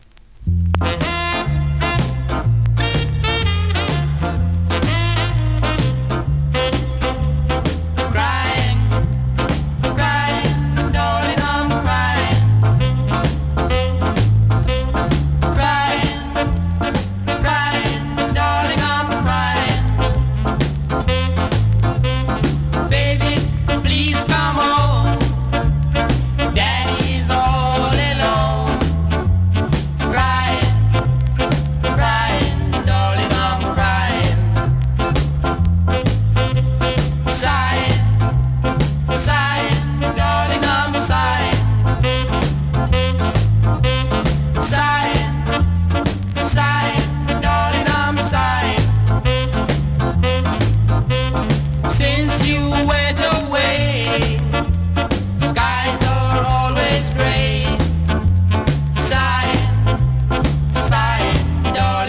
スリキズ、ノイズ比較的少なめで
B面の試聴はこちらからどうぞ。